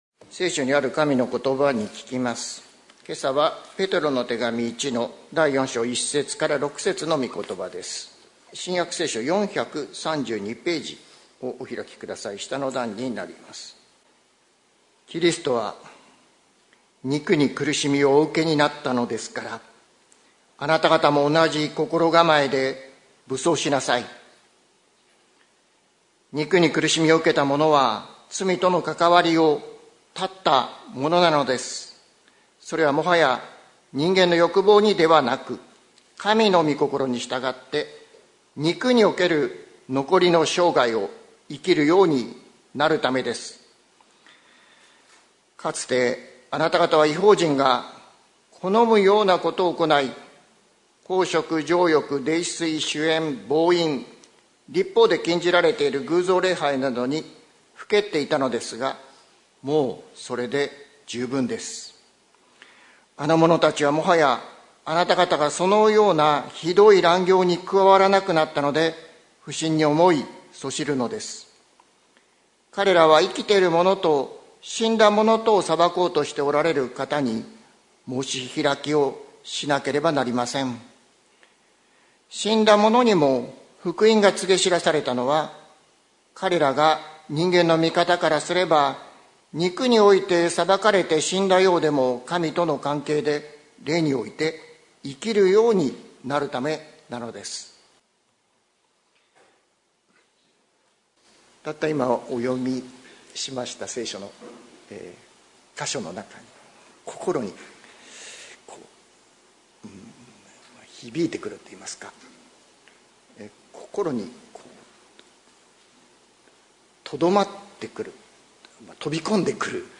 2025年01月19日朝の礼拝「欲望ではなく神のみ心に」関キリスト教会
説教アーカイブ。